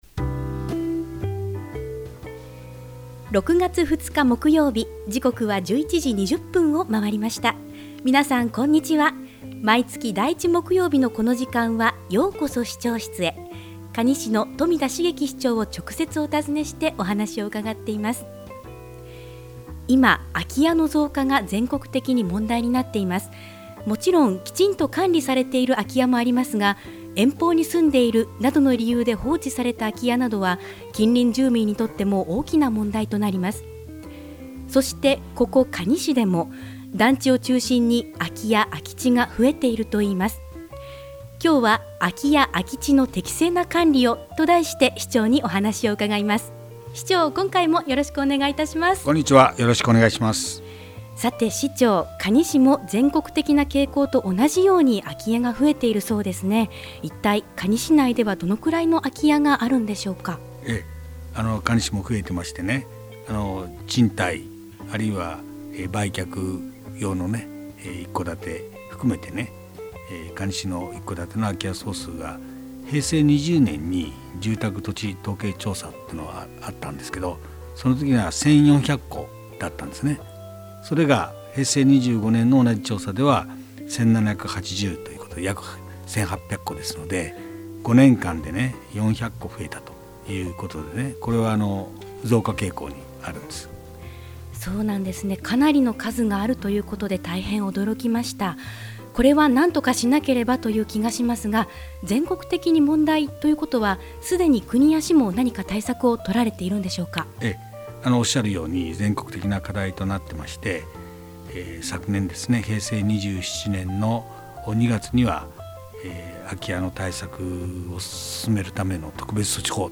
ようこそ市長室へ 2016-06-02 | ようこそ市長室へ 「ようこそ市長室へ」 可児市長室へ直接伺って、まちづくりの課題、魅力ある地域、市政情報などを 中心に、新鮮な情報を 冨田市長の生の声を、皆様にお届けする番組です。 ◎ 放送時間 毎月第１木曜日 １１：２０～ 可児市長 ： 冨田 成輝 ▼ 平成２８年 ６月 ２日 放送分 【今回のテーマ】 「空き家・空き地の適正な管理を」 Podcast: Download « ようこそ市長室へ ようこそ市長室へ »